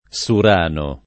Surano [ S ur # no ] top. (Puglia)